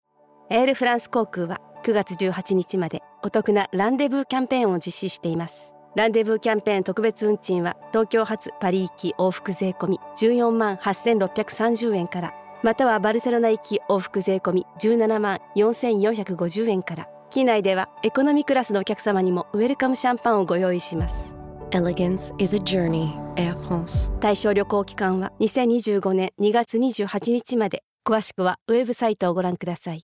Voix off
Sony Xperia - pub